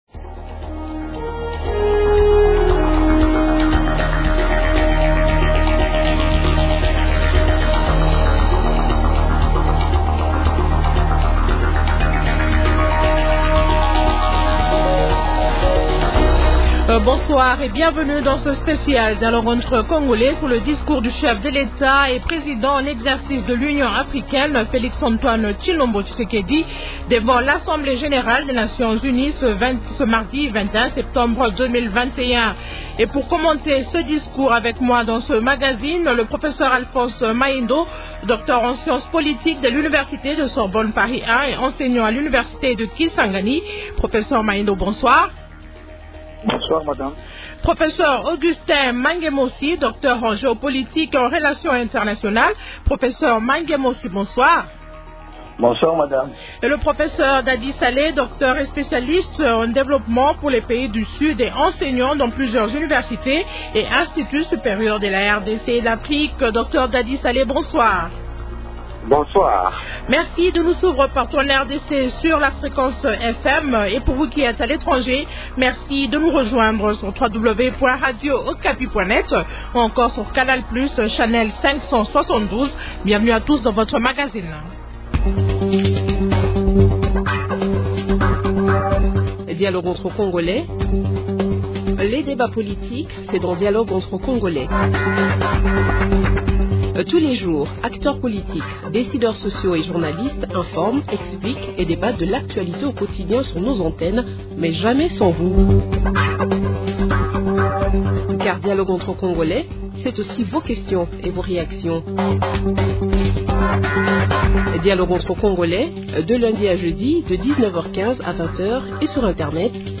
Spécial Dialogue entre congolais sur le Discours du chef de l’Etat congolais Félix Tshisekedi à la 76ème session de l’Assemblée générale des Nations-unies à New-York aux Etats-Unis, ce mardi 21 septembre.